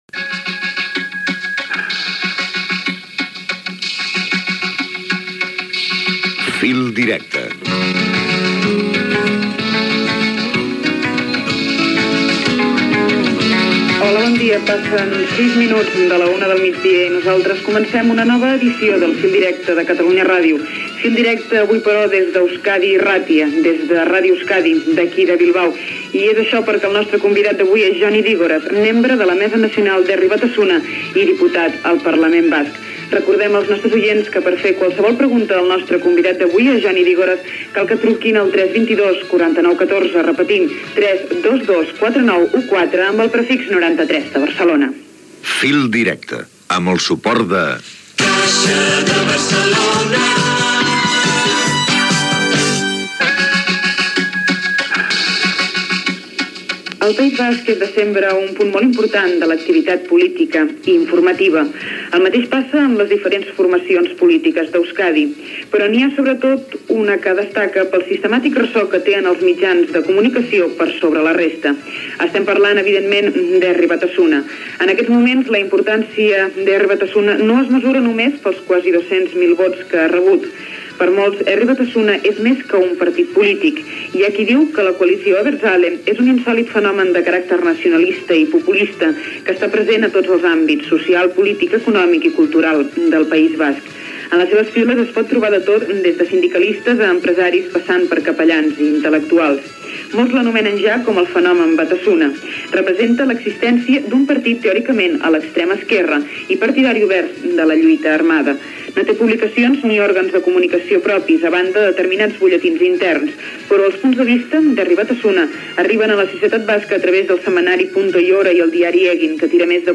Identificació del programa, hora, espai fet des d'Esuskadi Irratia per entrevistar al diputat al Parlament Basc Jon Idígoras de la coalició Herri Batasuna. Contextualització de la coalició i primers minuts de l'entrevista. Indicatiu i publicitat
Informatiu